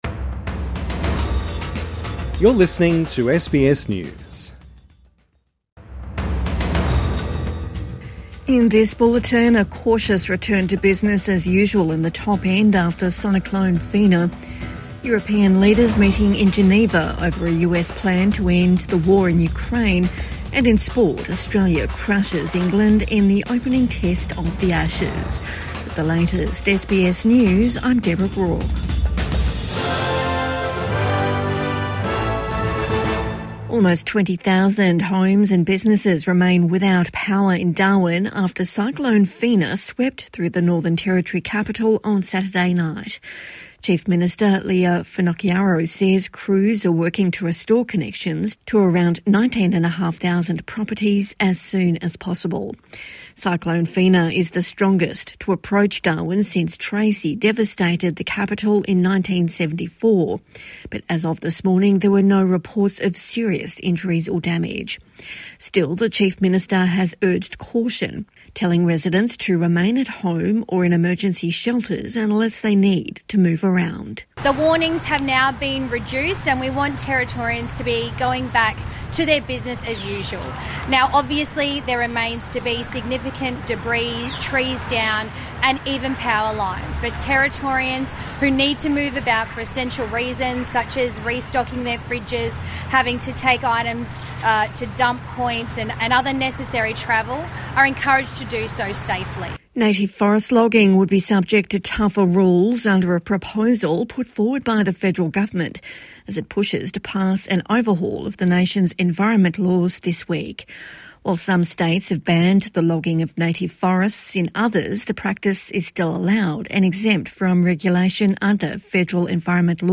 European leaders to meet over US Ukraine plan | Evening News Bulletin 23 November 2025